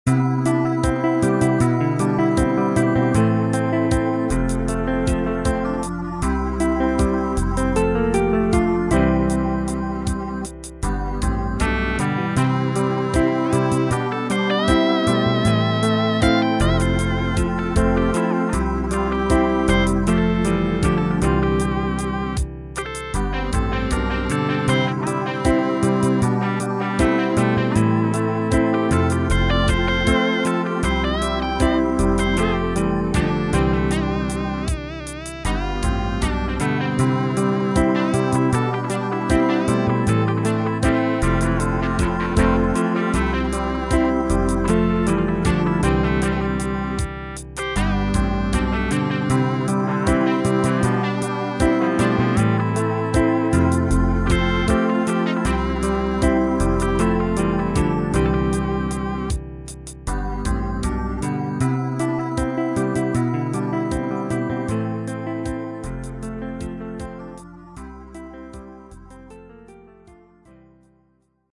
Reggae 70's
MIDI